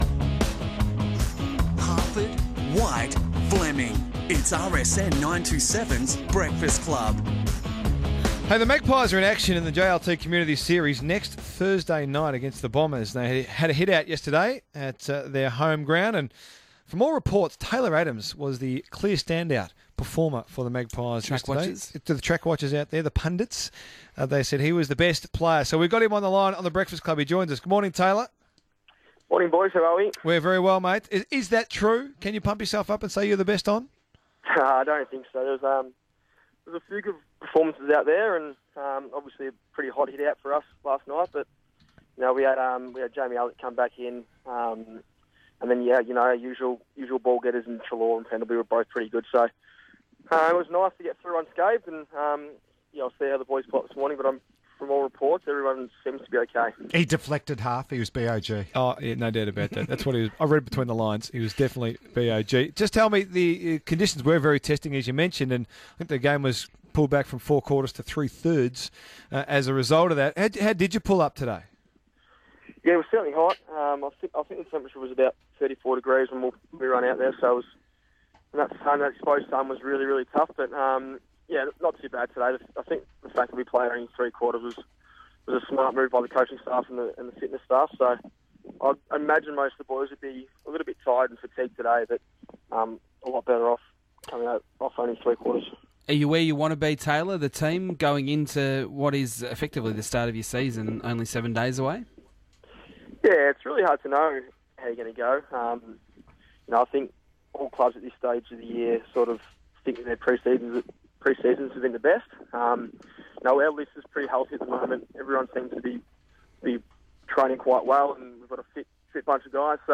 Listen as Taylor Adams chats to RSN Breakfast the morning after Collingwood's intra-club match at the Holden Centre.